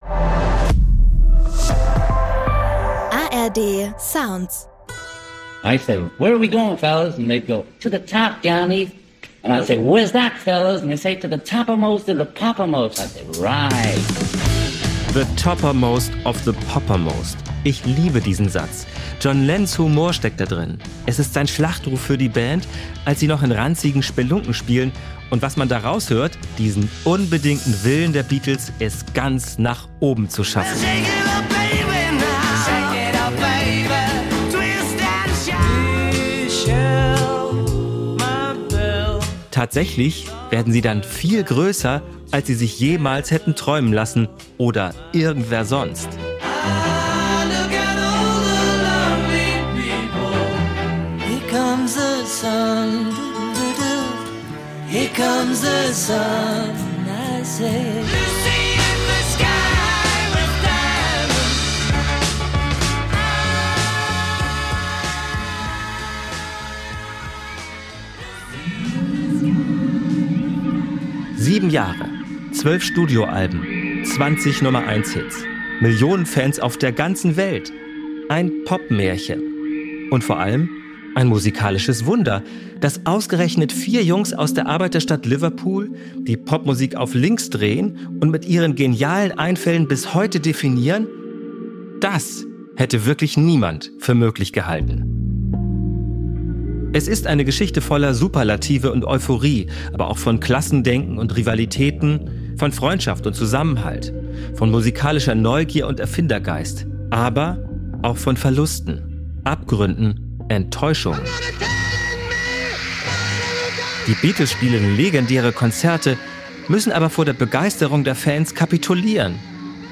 Und fragt den bekannten Musikjournalisten David Hepworth, was dieses gewisse Etwas ist, das die "Four Lads from Liverpool" zur berühmtesten Band der Welt gemacht hat.